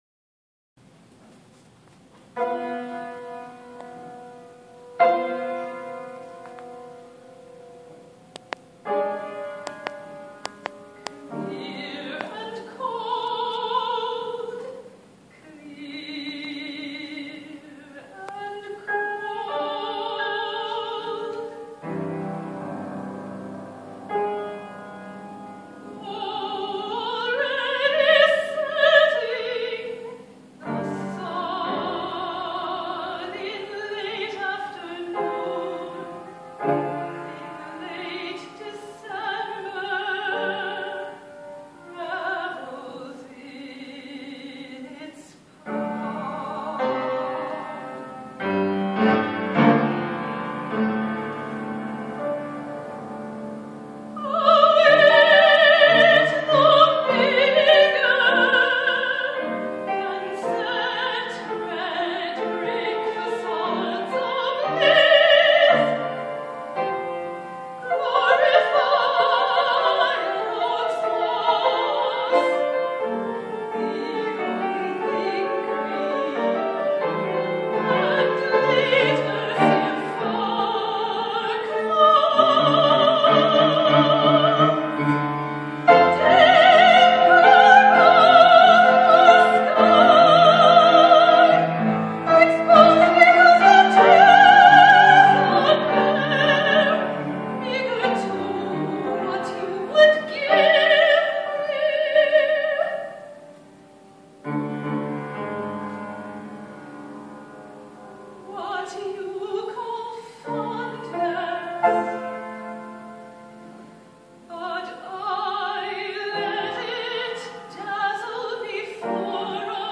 song cycle
also the pianist in the recording
soprano